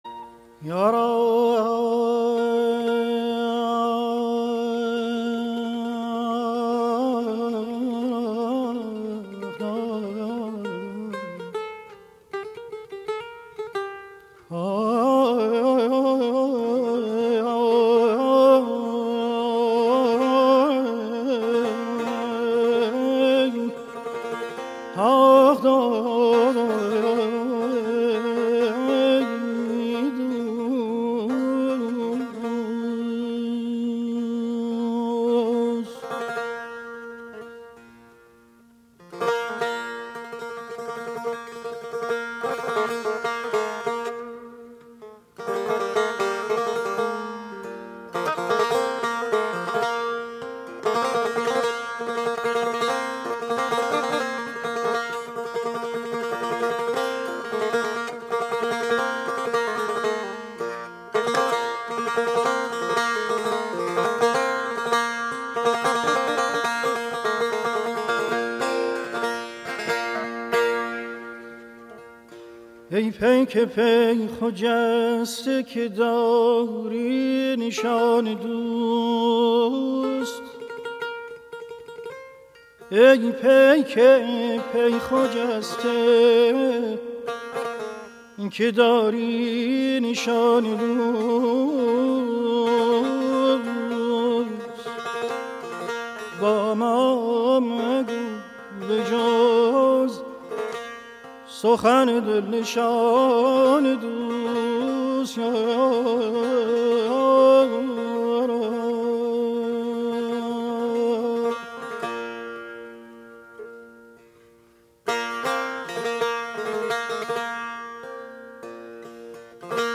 ساز و آواز بیات زند
سه‌تار